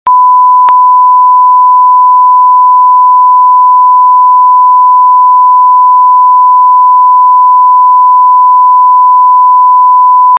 1khz.mp3